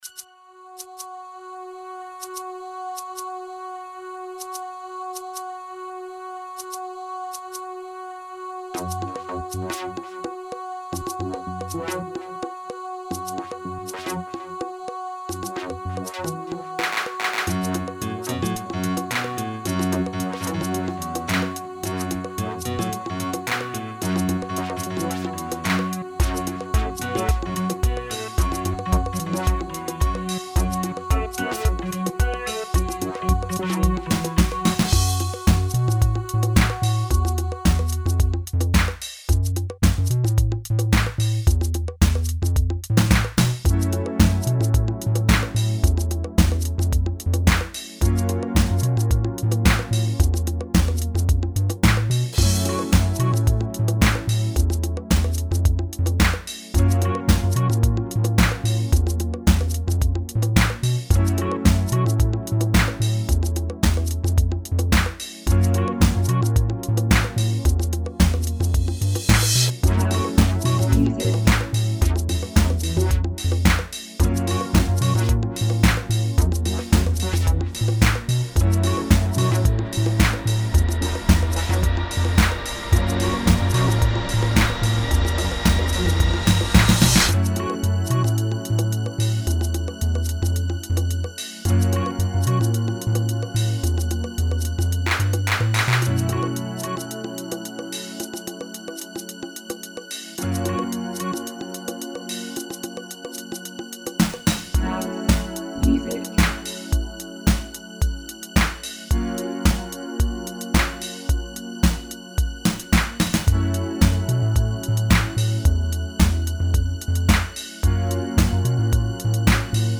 Under julen roade jag mig med att snickra ihop en liten house-låt.
Lite knepigt med synth-flöjt-ljudet bitvis, tycker inte det ligger riktigt bra.
I övrigt låter det snyggt och polerat. Ingen riktig punch, men jag tror det beror på brist på kompression och dist (ingen komprssion alls används, allt är rakt in i mixern på 16/44,1, sedan mixat i Sonar LE).
Korg Trinity
Nord Lead 2x
Machinedrum
Vocovee VST voocorder
+ någon crash-symbal som jag inte vet varifrån den kommer.